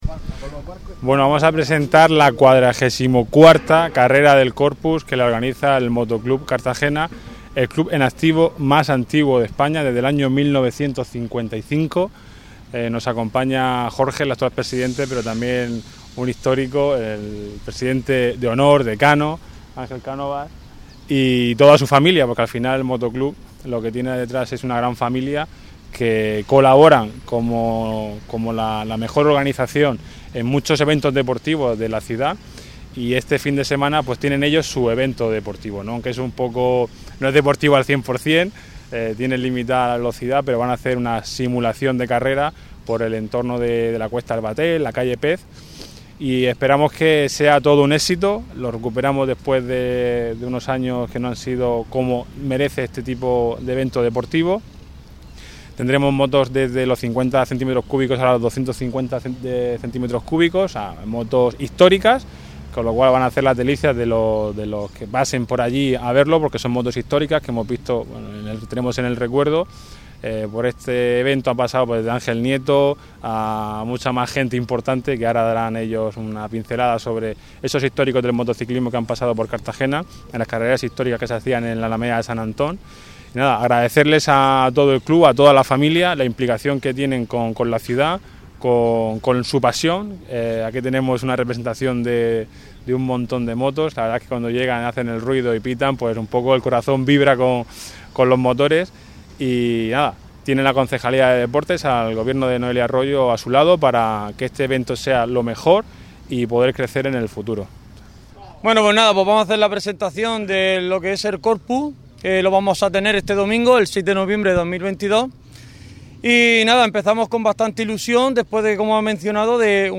Enlace a Presentación del XLIV Trofeo Corpus